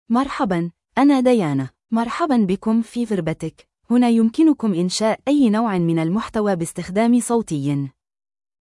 FemaleArabic (Standard)
DianaFemale Arabic AI voice
Diana is a female AI voice for Arabic (Standard).
Voice sample
Listen to Diana's female Arabic voice.
Diana delivers clear pronunciation with authentic Standard Arabic intonation, making your content sound professionally produced.